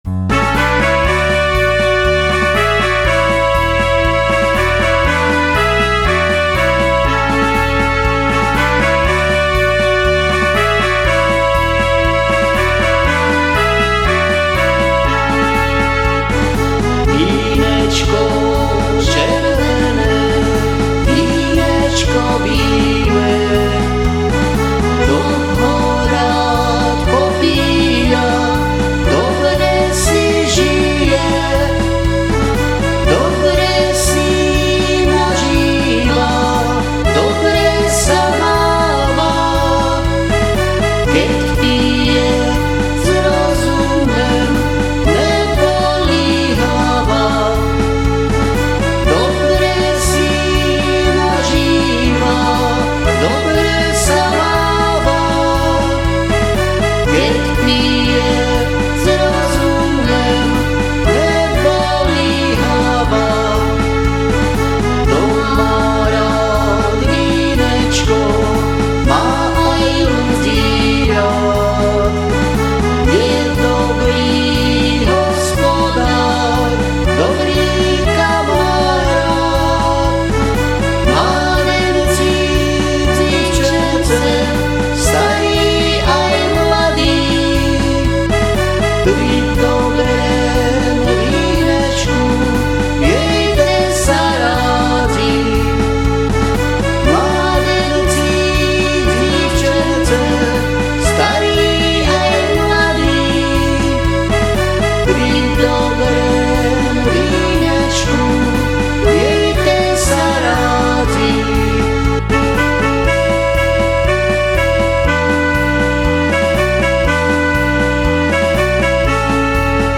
Som amatérsky muzikant, skladám piesne väčšinou v "záhoráčtine" a tu ich budem prezentovať.
CD 2 - spoločný duet - taká malá oslava vína